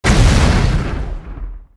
GrenadeExplosion.mp3